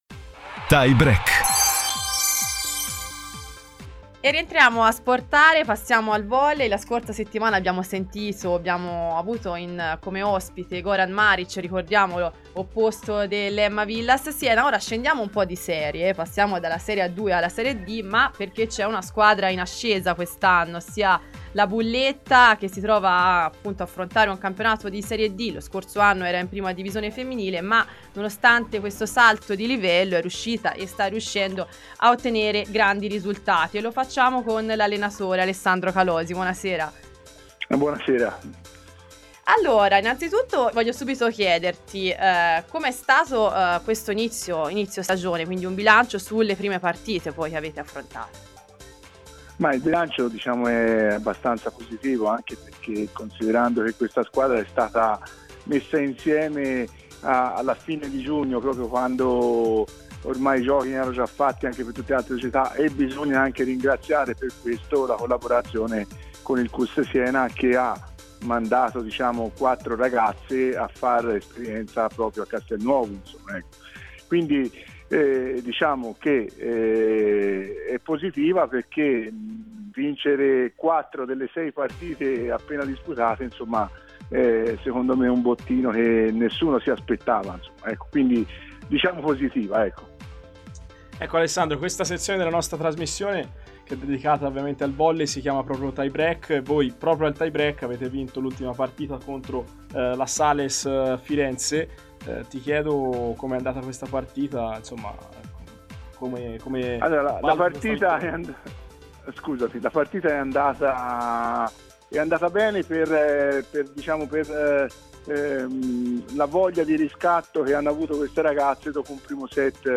Terzo appuntamento questo pomeriggio con “SportARE – Tutte le coniugazioni dello sport senese”, la nuova trasmissione dedicata allo sport in onda ogni giovedì dalle 18 alle 20 sulle frequenze di Antenna Radio Esse.